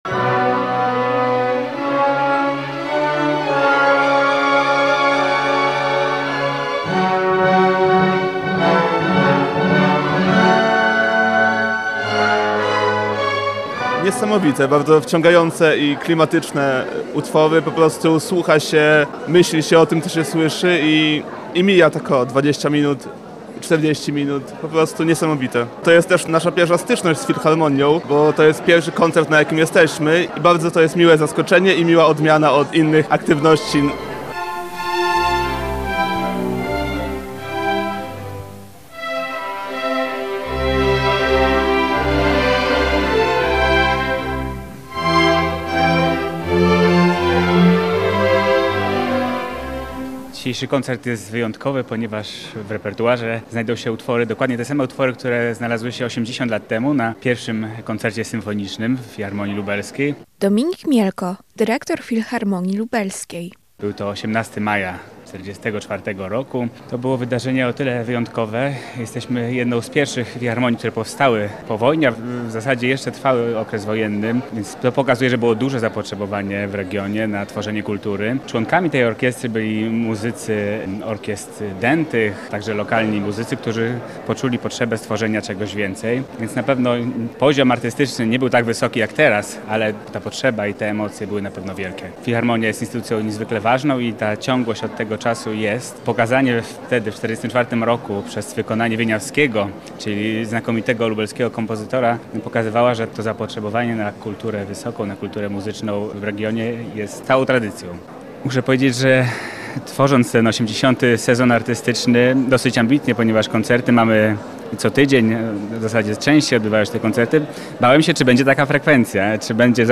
Na relację z wydarzenia